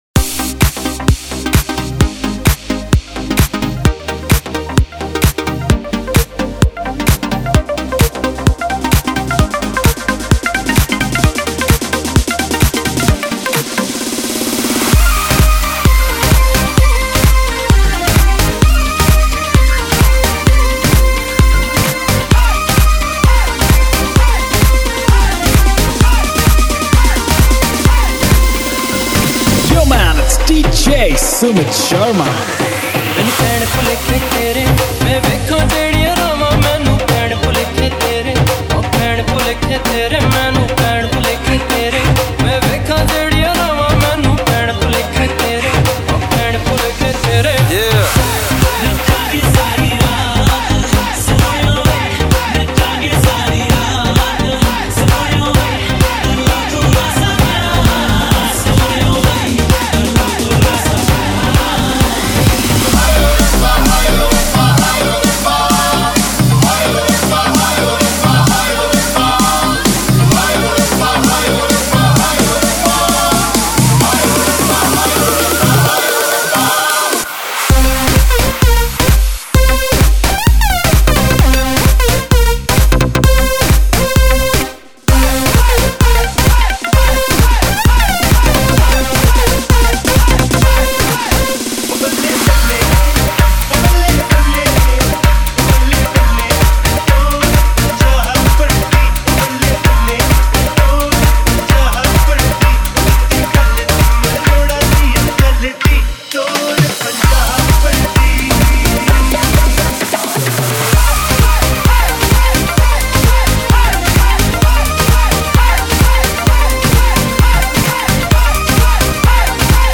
HomeMp3 Audio Songs > Others > Single Dj Mixes